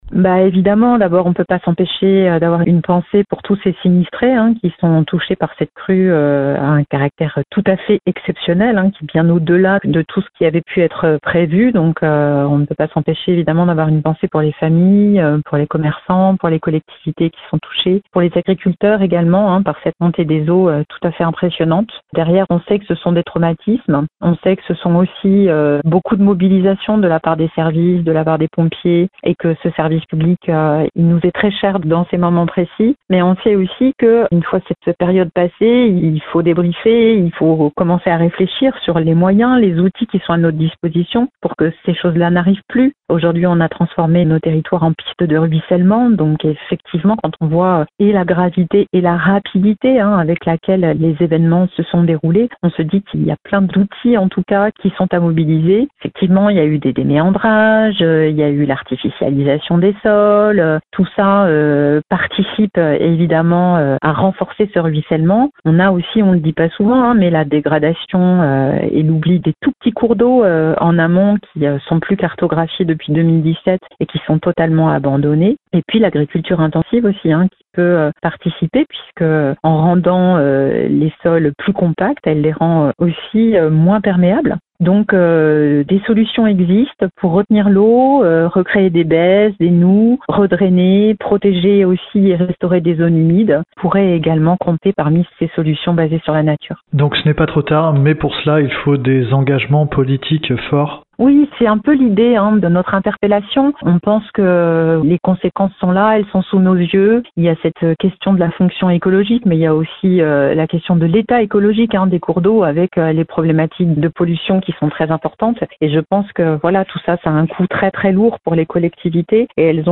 L’association souhaite les interpeller sur la protection et la gestion de la ressource, et leur propose de s’engager pour la reconnaissance juridique des droits du fleuve Charente, dans un contexte où celui-ci est soumis à des pressions croissantes qui fomentent ce type de catastrophe, comme le souligne sa porte-parole et conseillère régionale écologiste Katia Bourdin :
bourdin-charente.mp3